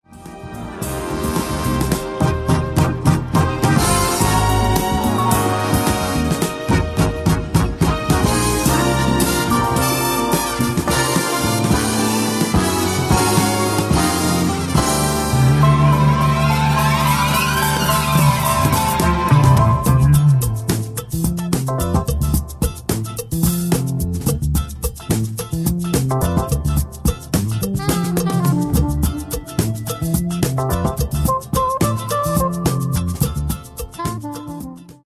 Genere:   Jazz Funk